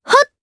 Leo-Vox_Jump_jp.wav